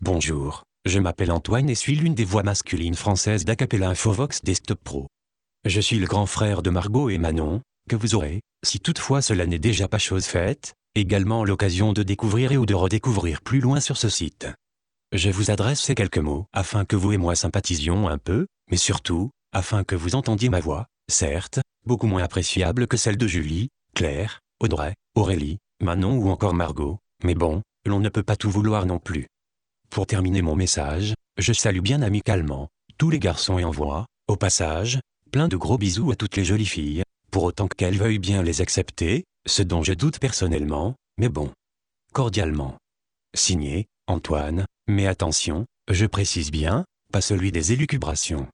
Texte de démonstration lu par Antoine, voix masculine française d'Acapela Infovox Desktop Pro
Écouter la démonstration d'Antoine, voix masculine française d'Acapela Infovox Desktop Pro